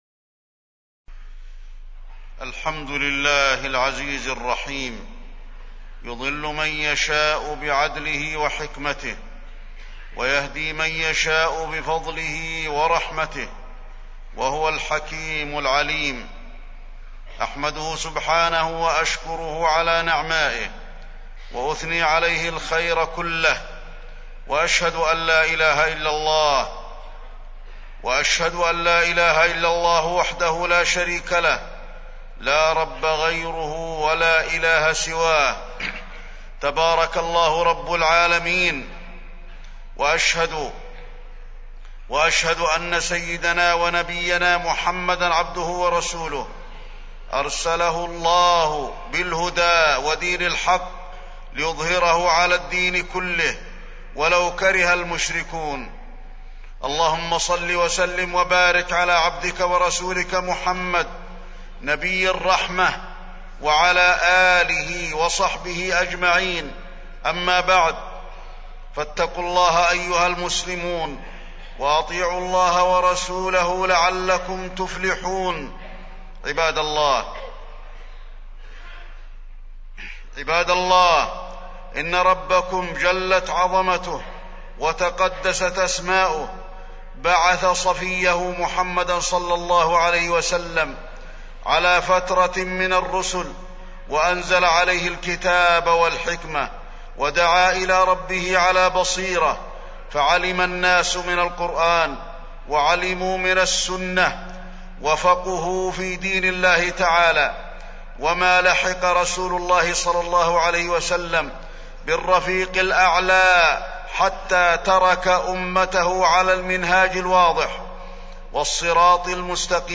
تاريخ النشر ١١ محرم ١٤٢٧ هـ المكان: المسجد النبوي الشيخ: فضيلة الشيخ د. علي بن عبدالرحمن الحذيفي فضيلة الشيخ د. علي بن عبدالرحمن الحذيفي صفات الفرقه الناجية